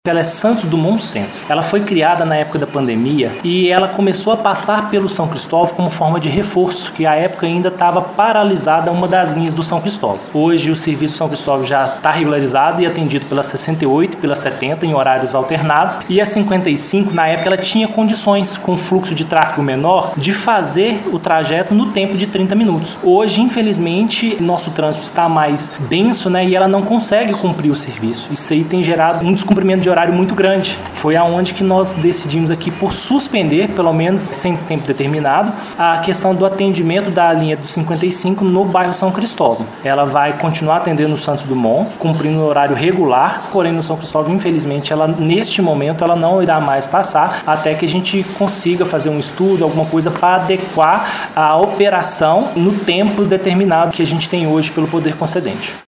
Moradora do São Cristóvão